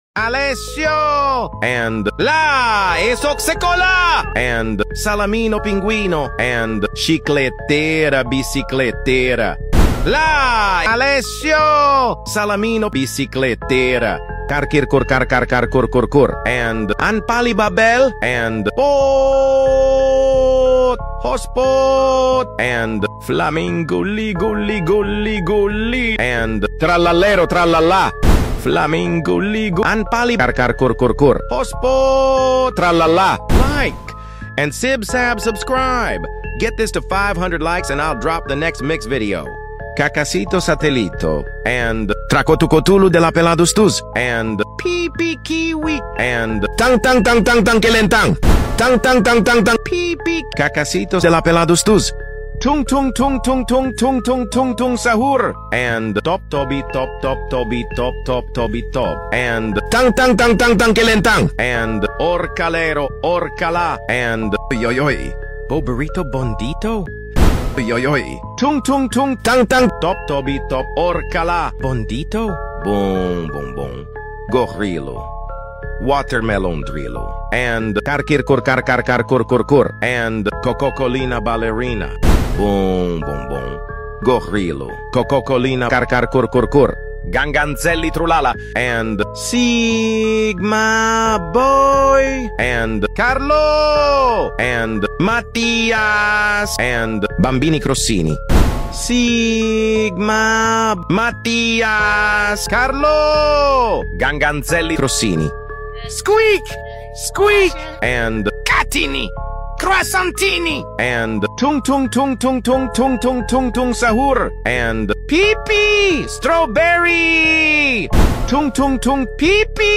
Italian Brainrot but They are all Mixed
You Just Search Sound Effects And Download. tiktok sound effects meme Download Sound Effect Home